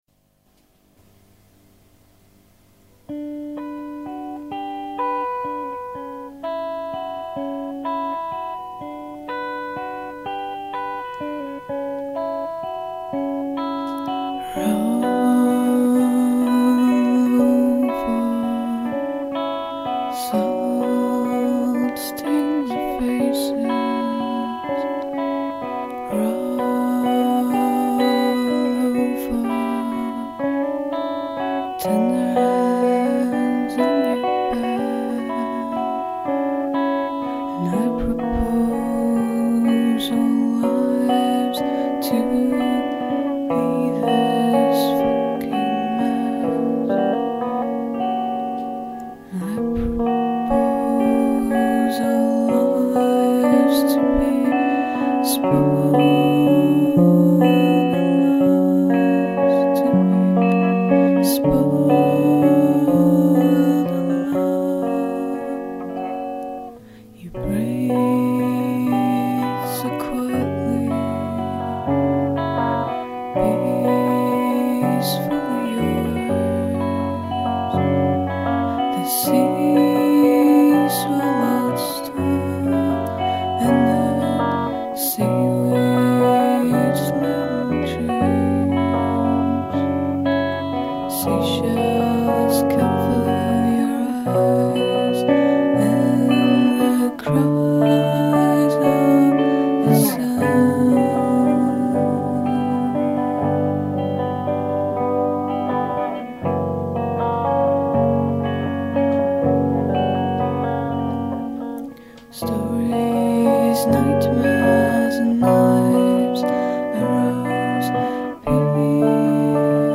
gorgeous ethereal